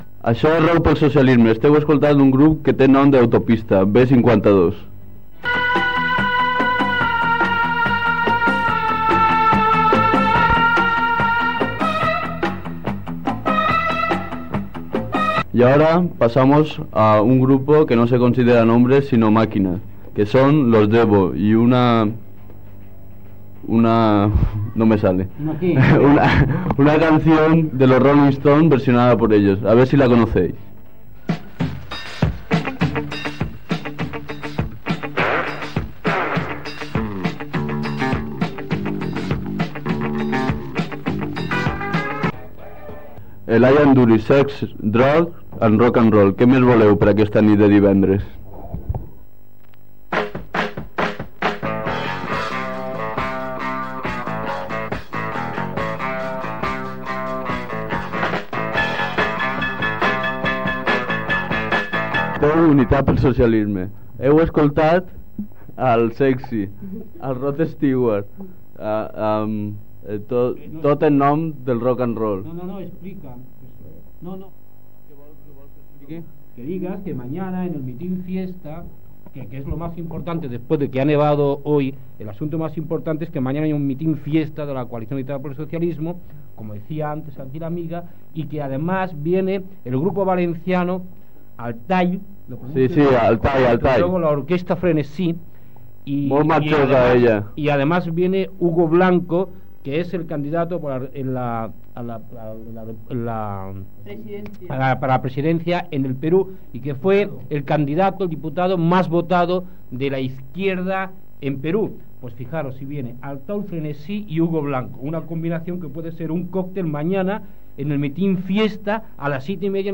Programació musical, lemes de campanya, anunci míting festa i final d'emissió.
FM